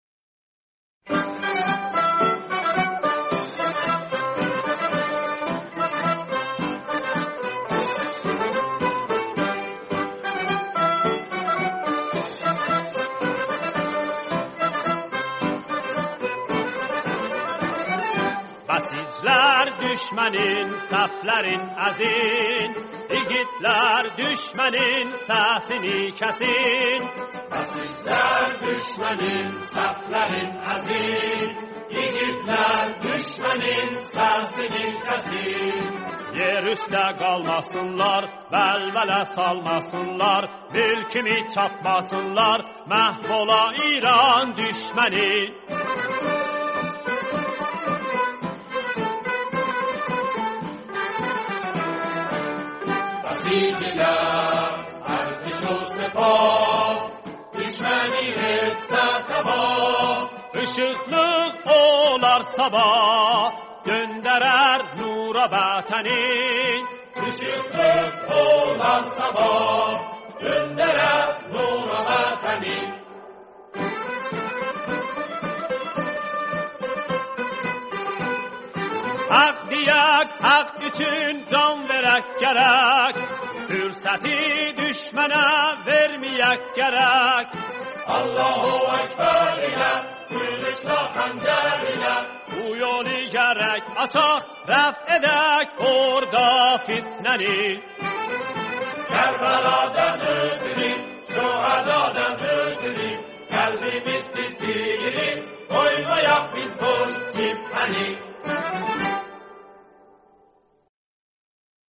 ترانه آذری با مضمون دفاع مقدس